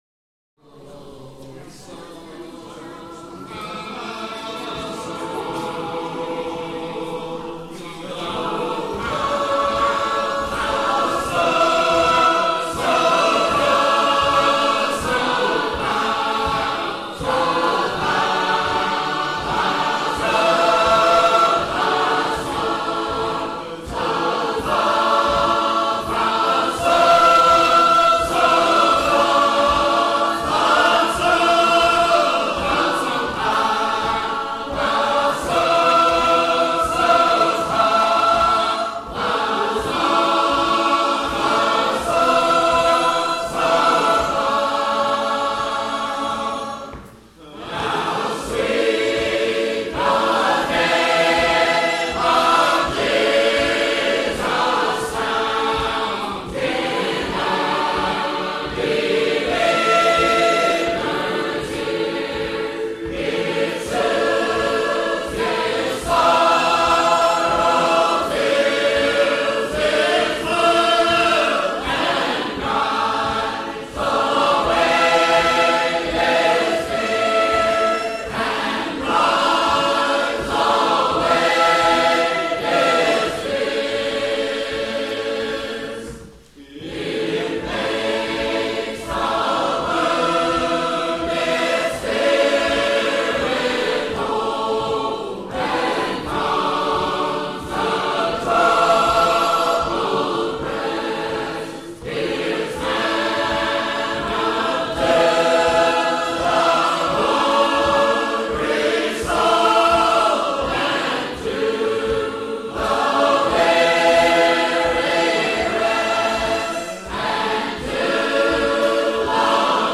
Rejoice with singers from the 1998 Missouri singing convention in a lovely country church near Marthasville, Missouri.
When they arrive, they bring the power of their voices as well as the joy of their fellowship.